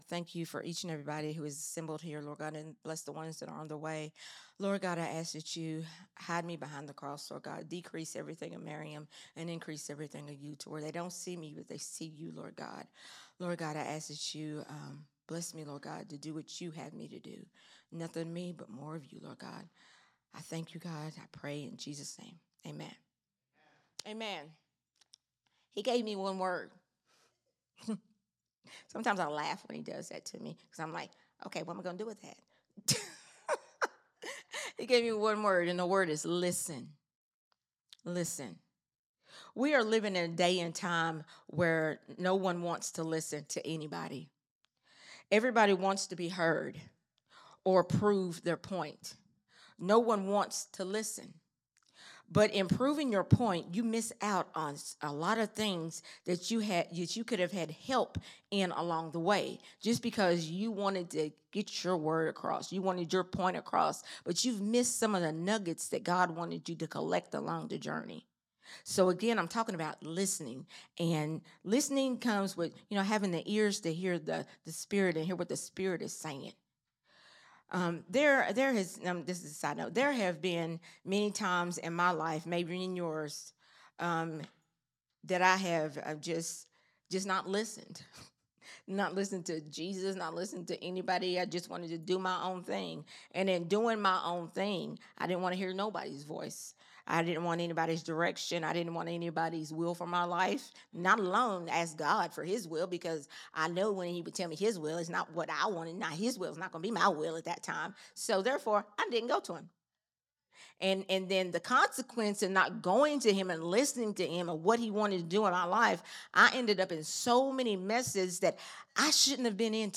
recorded at Growth Temple Ministries on Sunday, January 11, 2026.